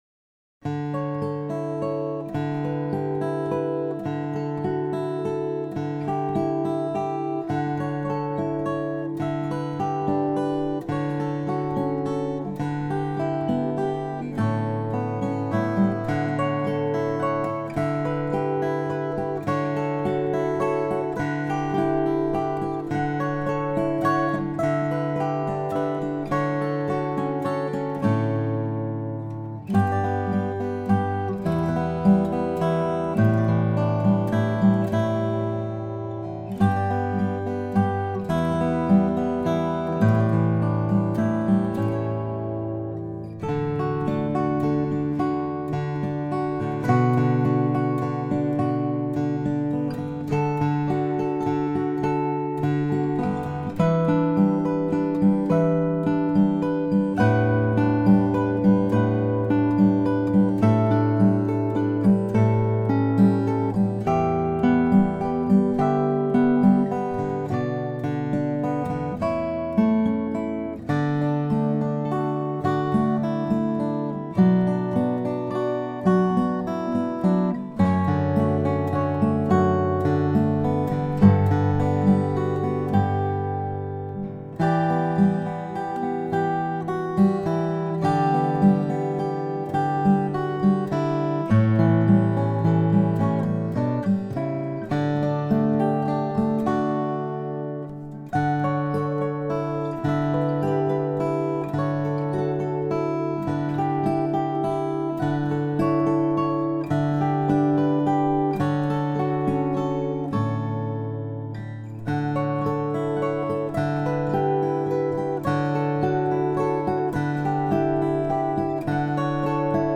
” and the tempo is considerably slower.